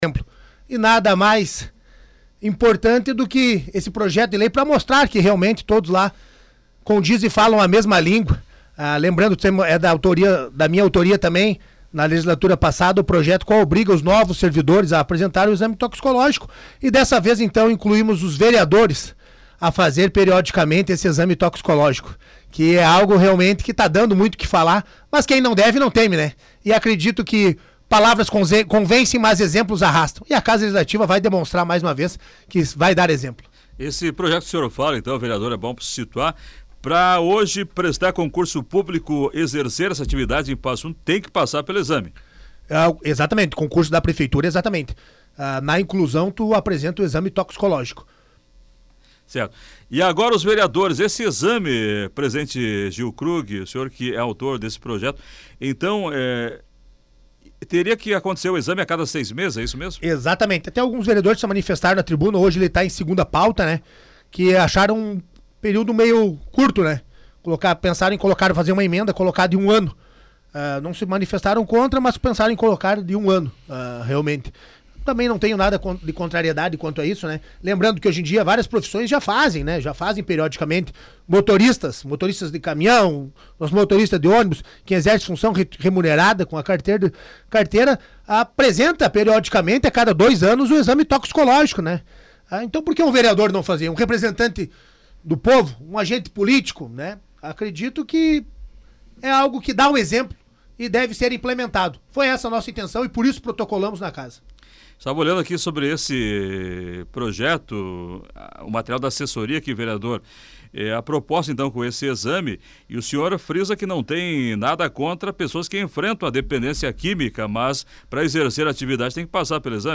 Nesta quarta-feira, 23, Krug esteve na Rádio Planalto News (92.1) para apresentar detalhes do projeto.
ENTREVISTA-VEREADOR-GIO-KRUG-23-04.mp3